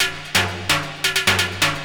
Index of /90_sSampleCDs/Ueberschall - Techno Trance Essentials/02-29 DRUMLOOPS/TE20-24.LOOP-ADDON+HIHAT/TE20.LOOP-ADDON2